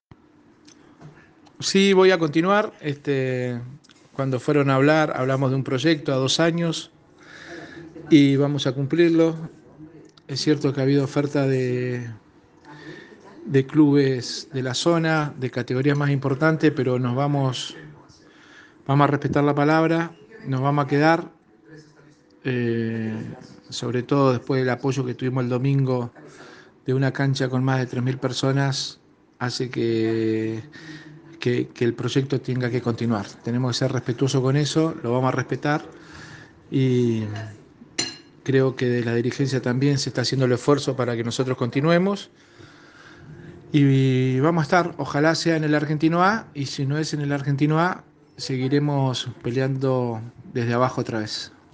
ENTREVISTA AL DT DE LA GRAN SORPRESA DEL FÚTBOL NEUQUINO